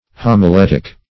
Homiletic \Hom`i*let"ic\, Homiletical \Hom`i*let"ic*al\, a. [Gr.